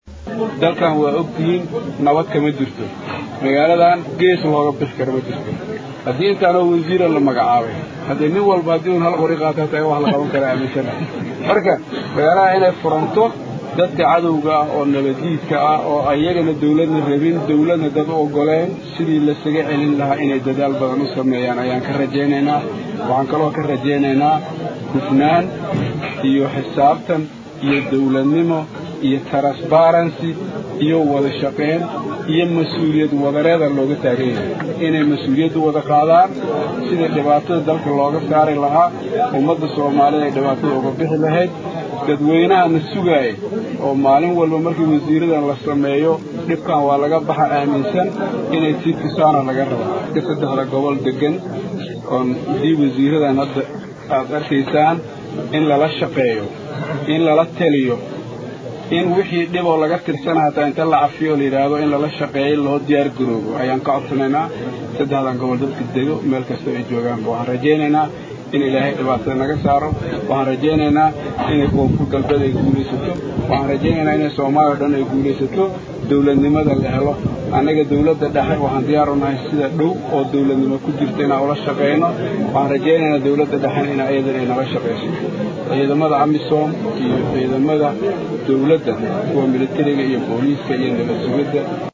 Shariif Xasan ayaa hadalkaan ka sheegay munaasabadii shalay sabtidii uu kusoo magacaabay Golihiisa Wasiirada Maamulak Koonfur Galbeed Soomaaliya.
Halkan Ka Dhageyso Codka Madaxweyne Shariif Xasan Sheekh Aadan.